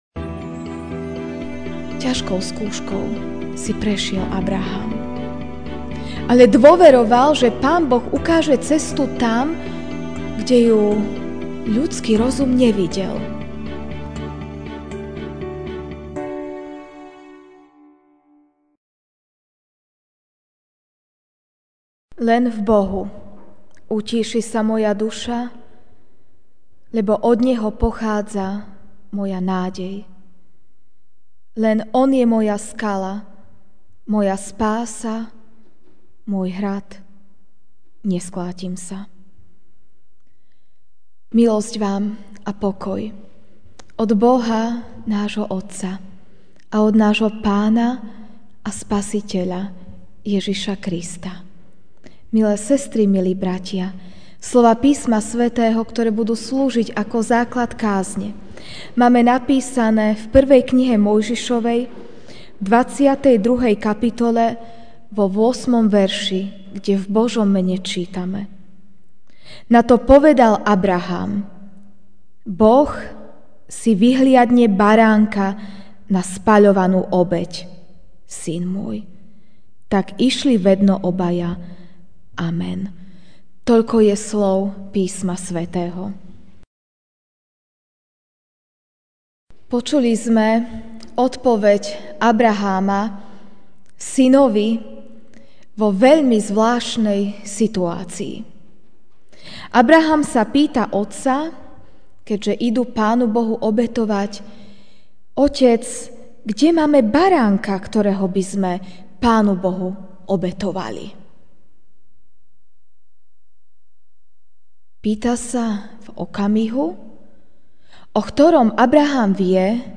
Večerná kázeň: Bremeno (1. M. 22, 8) Nato povedal Abrahám: Boh si vyhliadne baránka na spaľovanú obeť, syn môj.